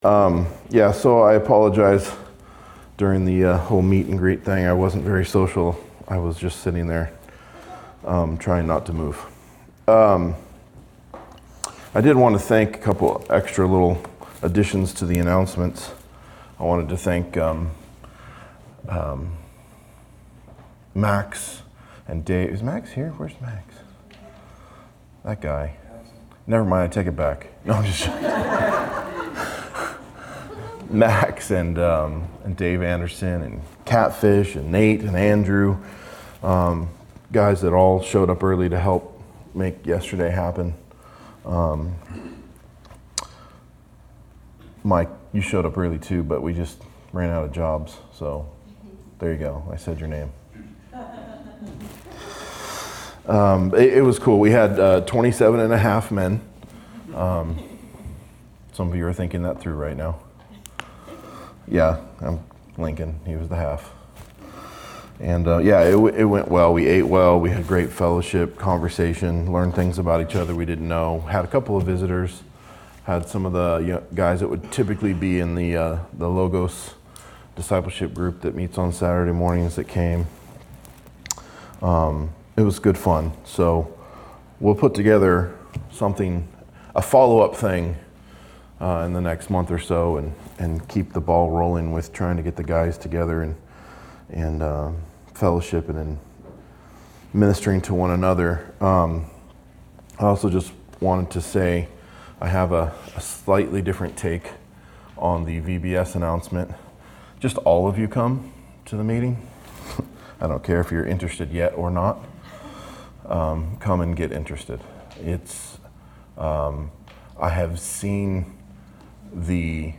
A message from the series "Matthew." Matthew 25:1-13